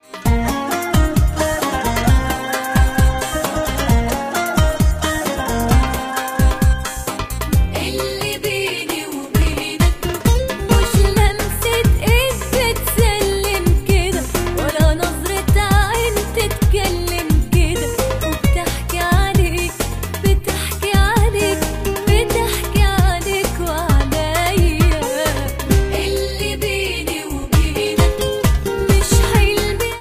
장르아랍 팝
악기보컬
이 버전은 더 높은 음조이고 2006년 앨범 버전보다 약간 더 빠르다.